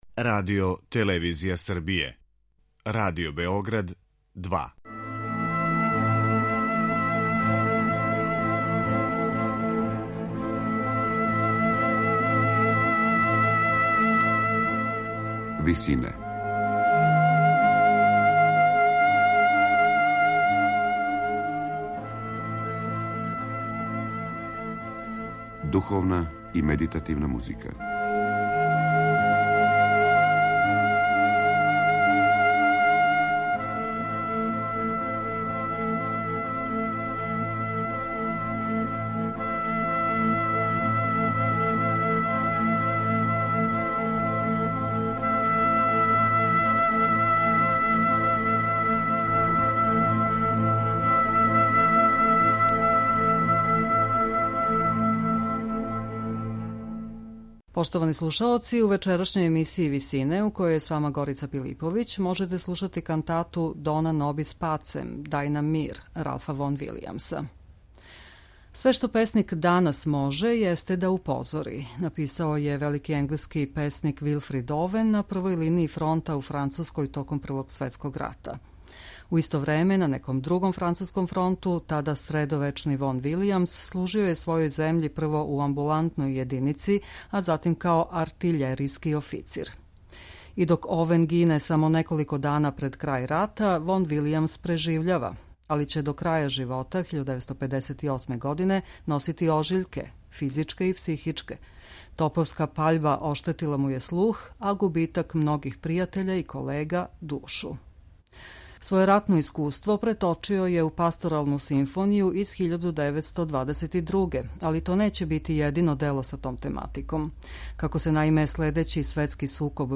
кантату
антиратну кантату